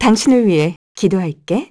Juno-Vox_Skill7_kr.wav